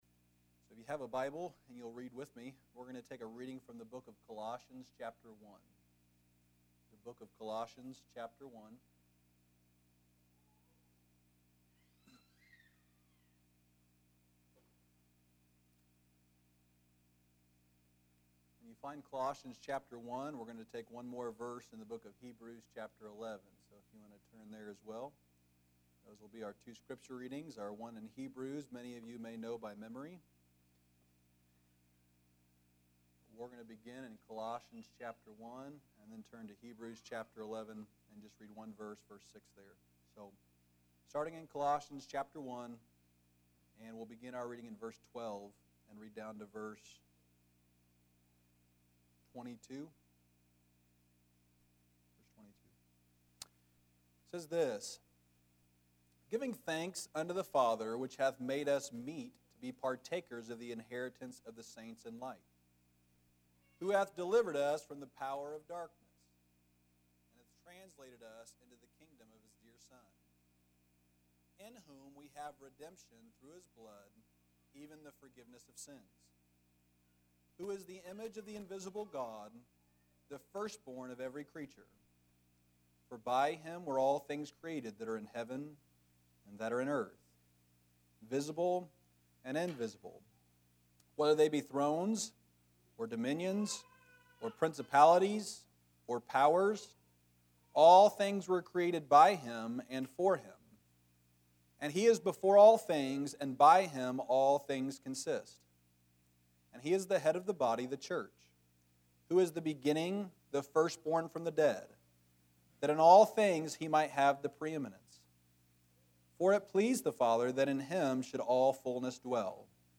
Passage: Colossians 1:12-22 Service Type: Revival